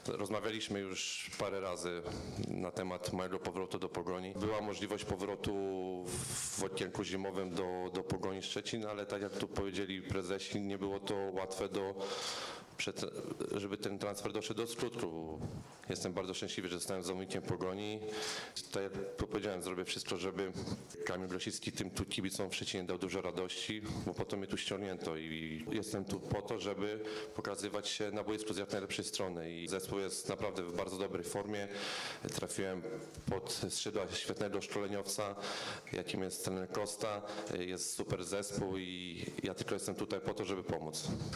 SZCZ-GROSICKI-KONFERENCJA-POGON.mp3